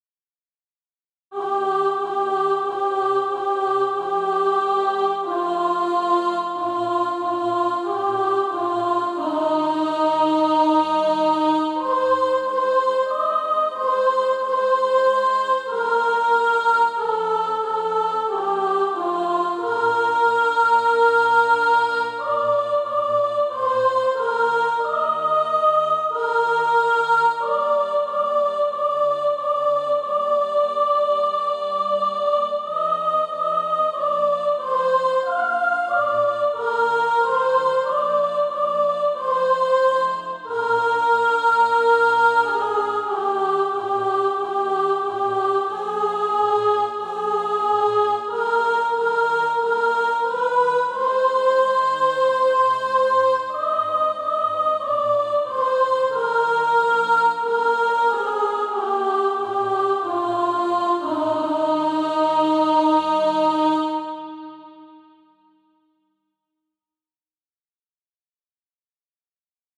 Soprano Track.
Practice then with the Chord quietly in the background.